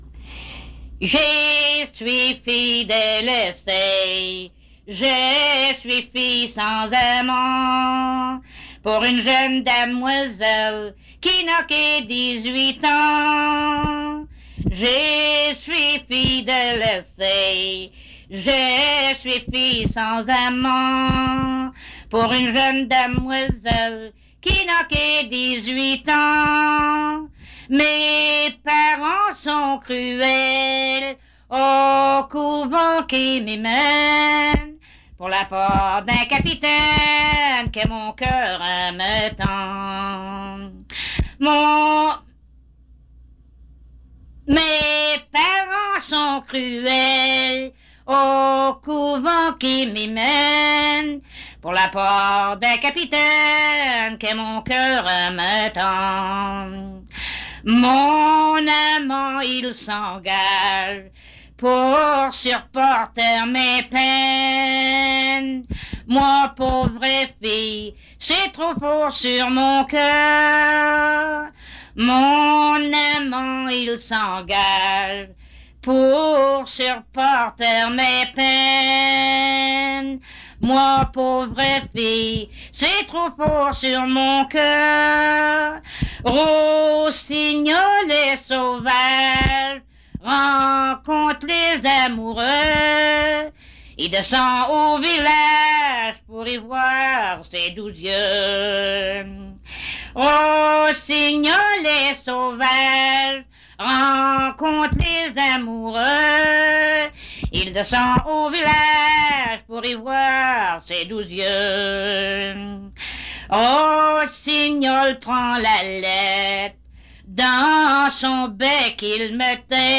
Chanson Item Type Metadata
Emplacement Cap St-Georges